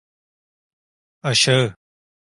Pronúnciase como (IPA) /aʃaˈɯ/